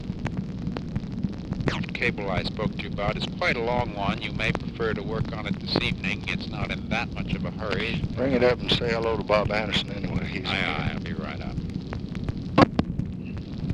Conversation with MCGEORGE BUNDY, June 10, 1965
Secret White House Tapes